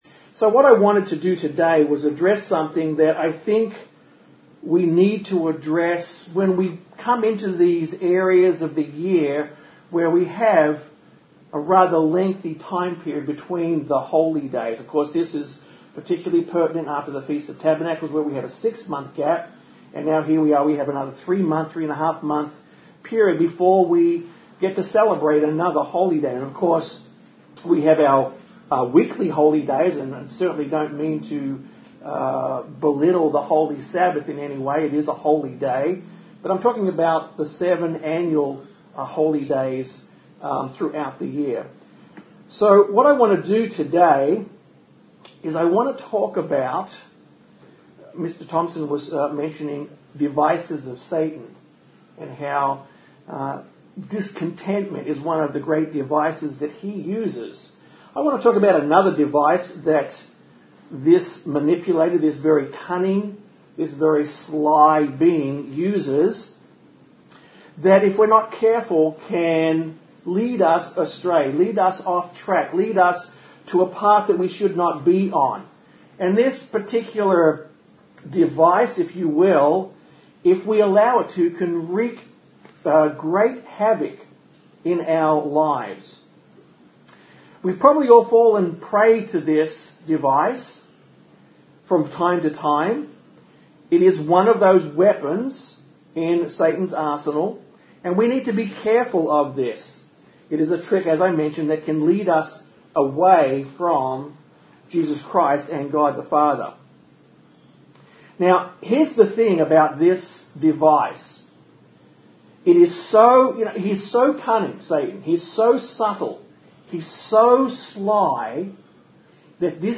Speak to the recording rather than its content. Given in Austin, TX